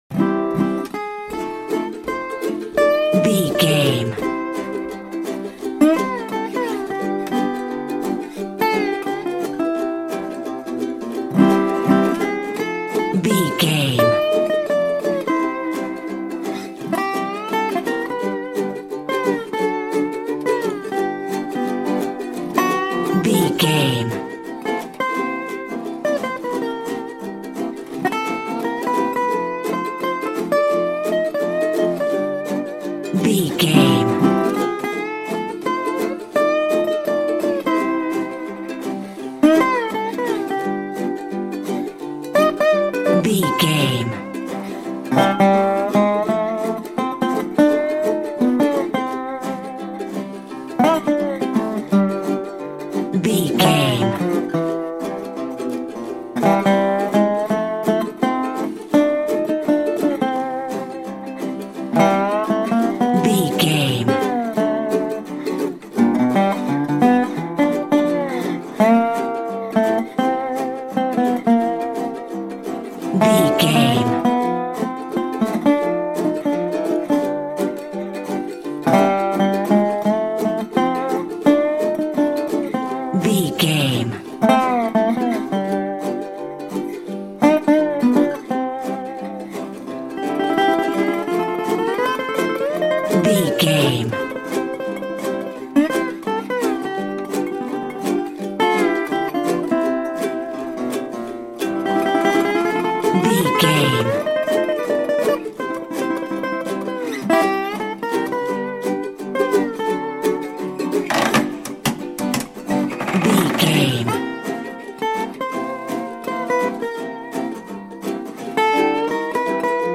Ionian/Major
acoustic guitar
banjo
percussion
ukulele
slack key guitar